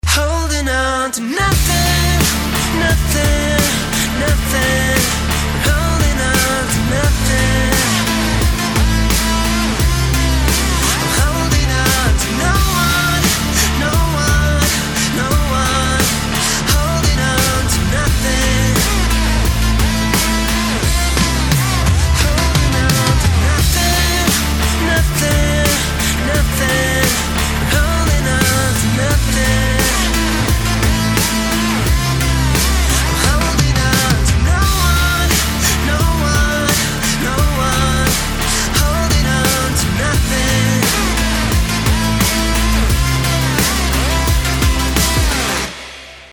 • Качество: 320, Stereo
мужской вокал
грустные
alternative
легкий рок